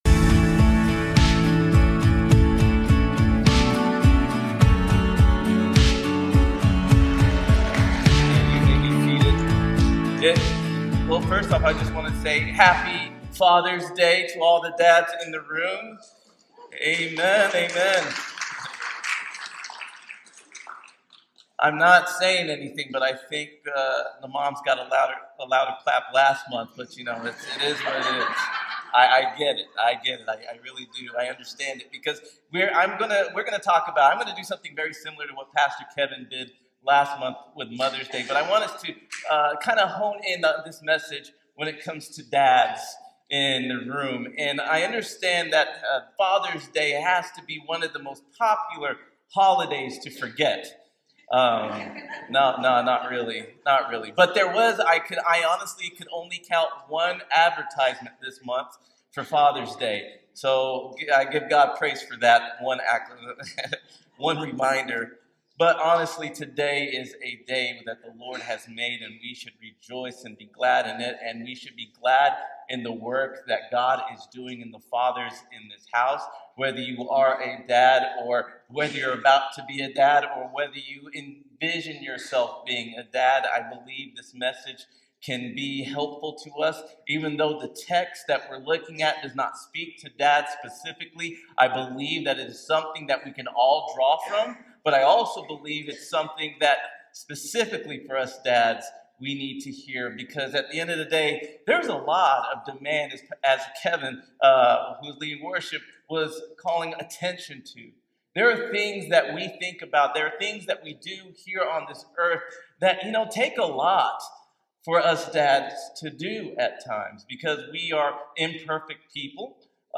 Delivered with honesty, empathy, and scriptural depth, the message explores three anchors for every father (and believer):